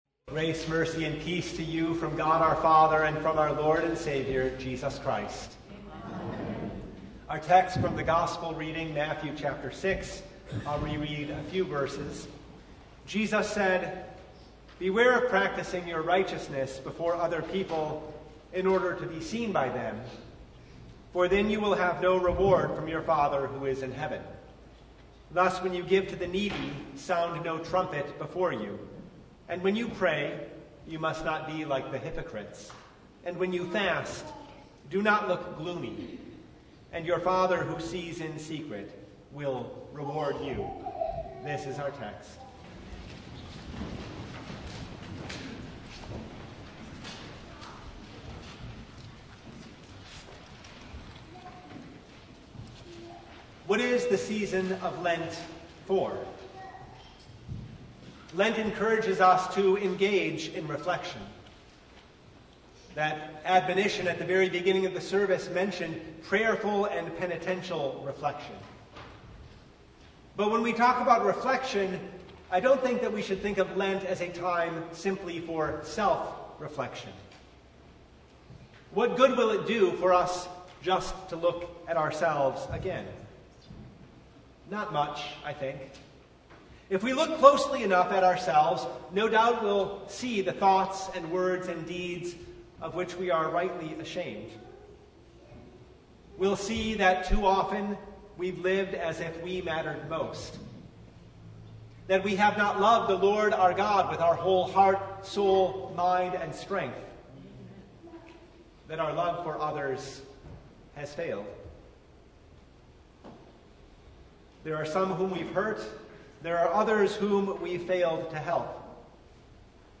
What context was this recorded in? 16-21 Service Type: Ash Wednesday Lent encourages us to engage in reflection